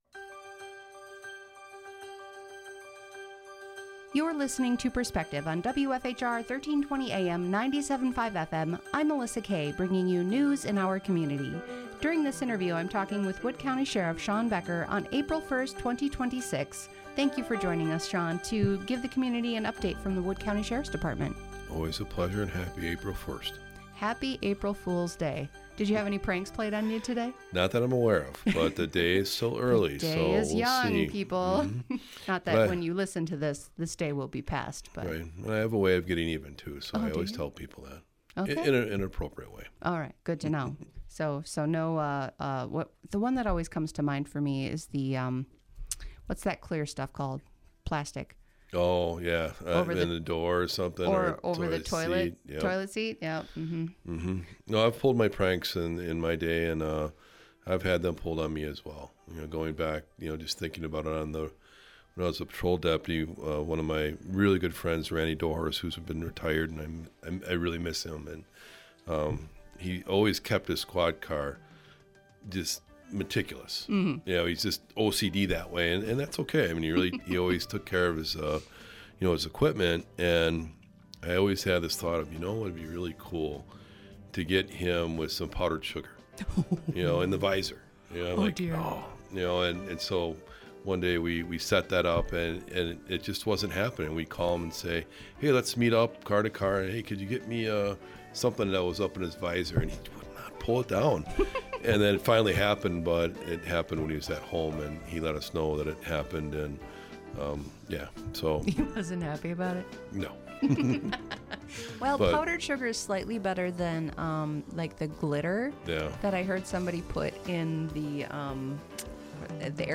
2026 23:26 Listen Share Wood County Sheriff Shawn Becker discusses a new crypto regulation bill aimed at curbing scams, now awaiting the governor's signature.